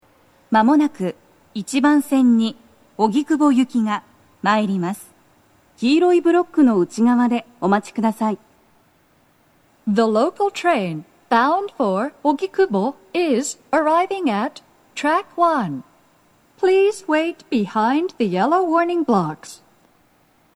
スピーカー種類 BOSE天井型
🎵接近放送
鳴動は、やや遅めです。
１番線 荻窪方面 接近放送 【女声
mshinkoenji1sekkinogikubo.mp3